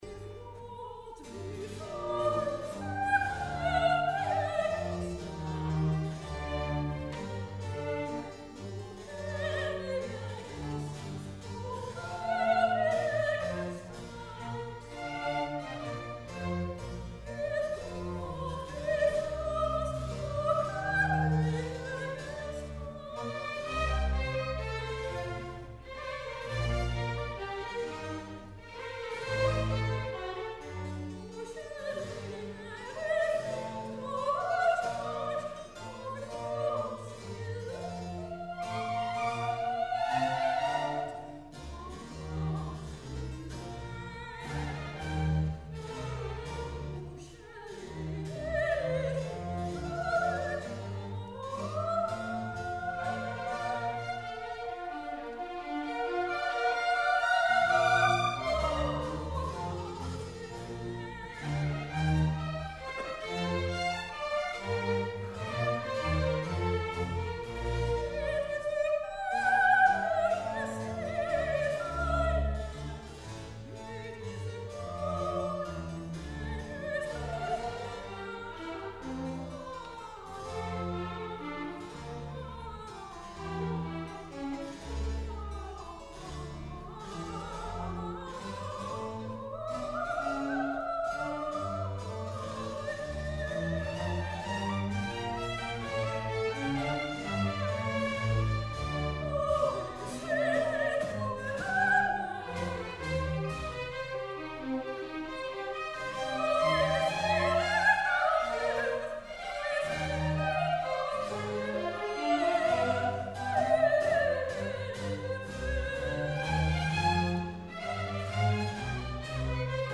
sopraan Muziekfragmenten